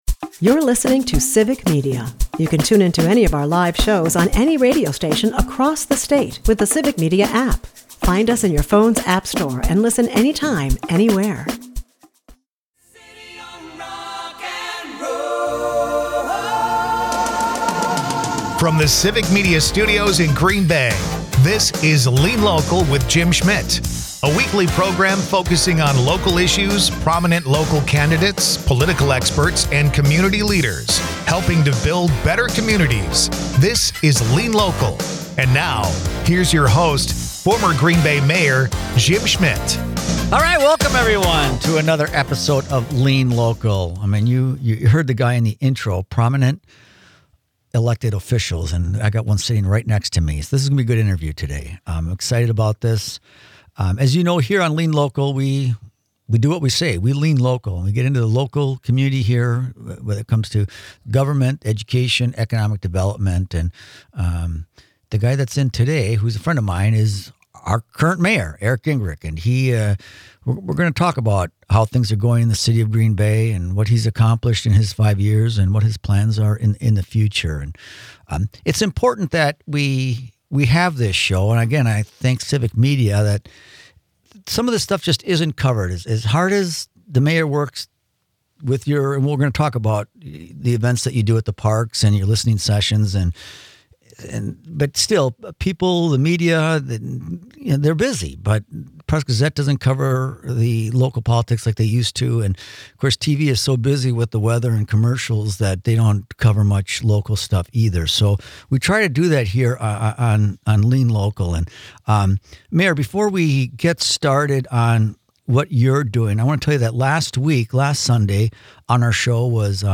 This week on Lean Local, Jim Schmitt welcomes Mayor Eric Genrich for a citywide checkup covering some of Green Bay’s biggest ongoing stories. The discussion begins with election season, as 109 local positions are now open for candidates.
It’s a broad and informative conversation about where the city stands today, and where it’s heading next.